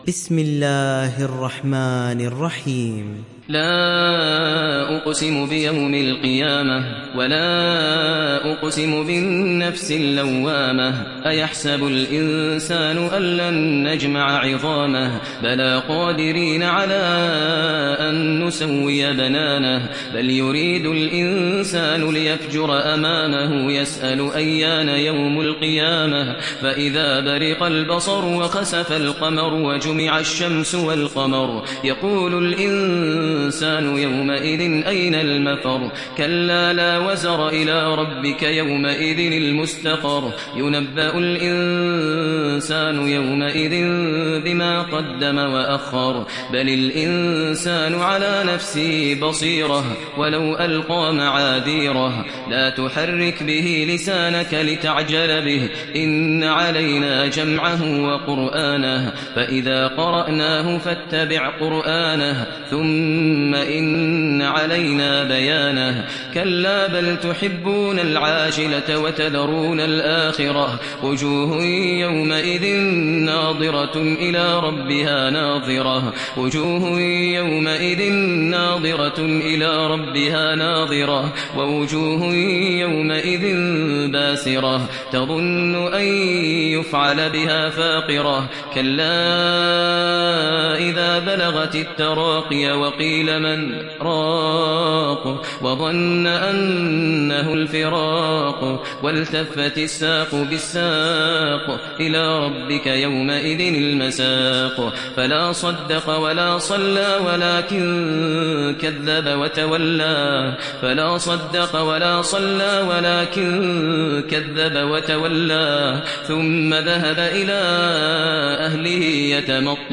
Surat Al Qiyamah mp3 Download Maher Al Muaiqly (Riwayat Hafs)
Download Surat Al Qiyamah Maher Al Muaiqly